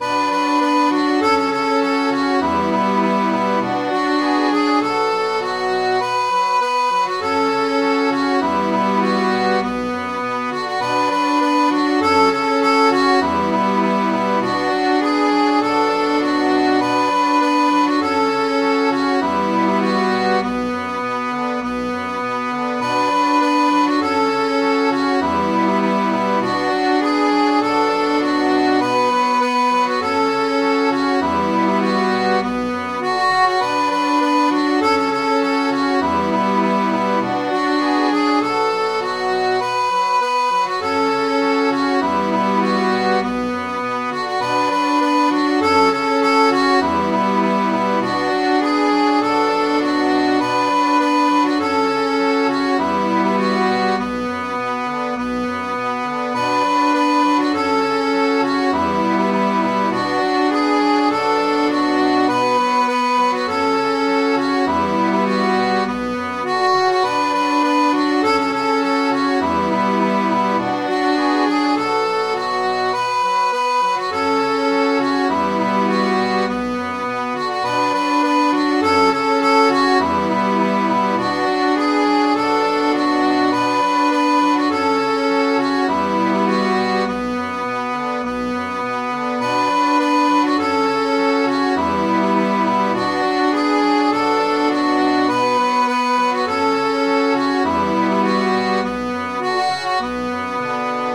Midi File, Lyrics and Information to Haul Away, Joe!
According to Stan Hugill, this is a famous tack and sheet shanty. Because of the many verses (he lists 20) he believes it may have been used as a halyard song (sheet shanties were usually no longer than three or four verses).